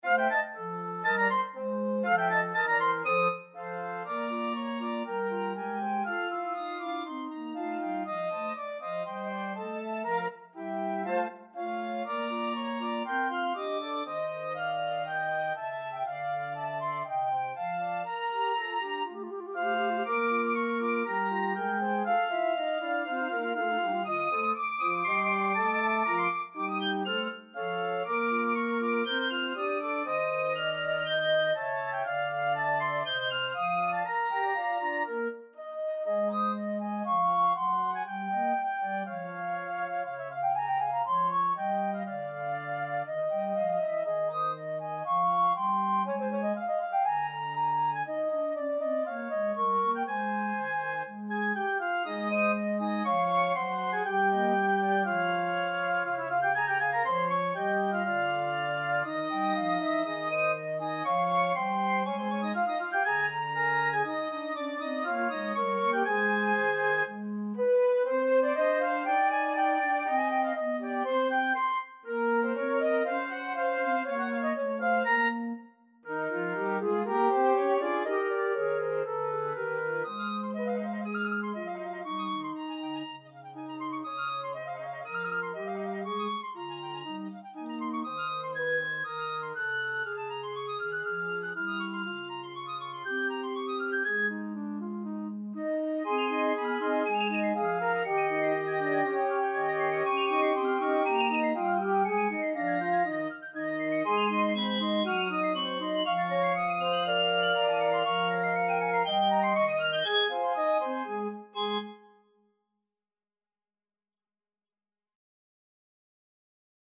piccolo solo, alto flute solo